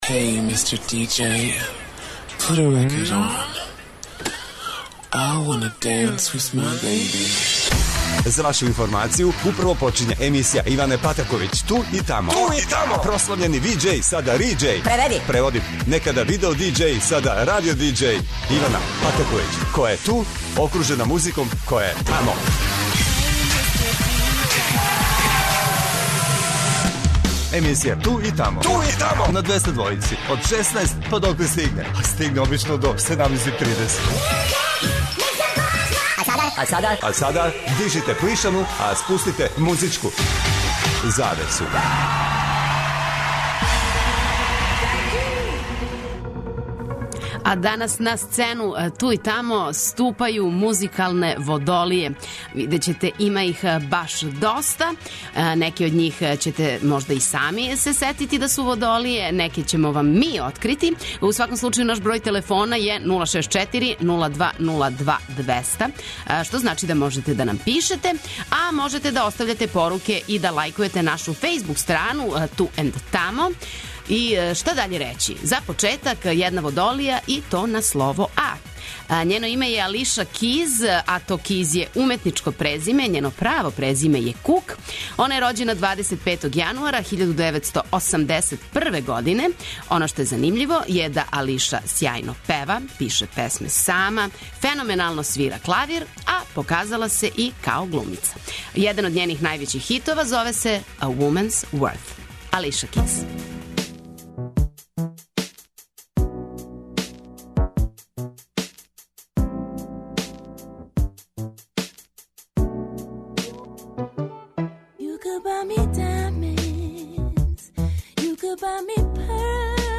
Као и сваке суботе слушаоци могу да се јављају и предлажу своје фаворите на задату тему.